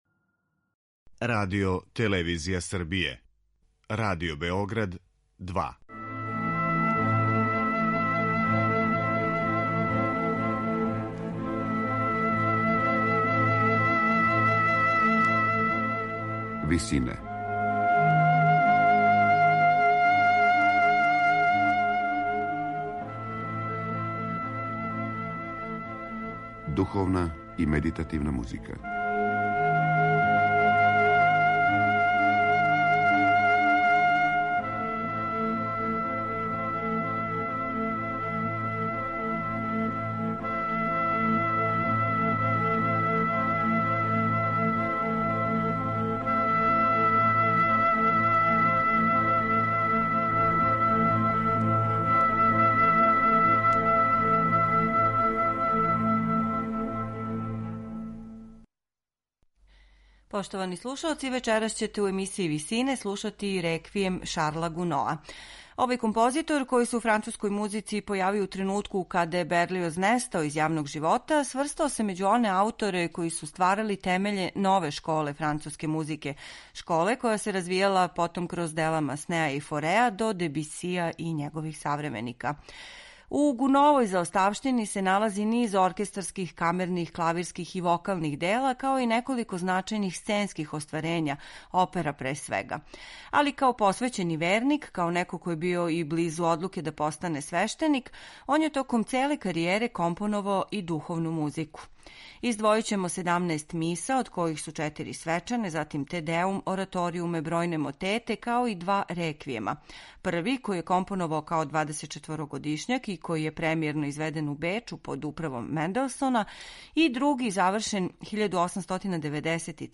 Снимак који ћемо вечерас емитовати остварили су вокални и инструментални ансамбл из Лозане. Дириговао је Мишел Корбос.